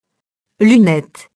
lunette [lynɛt]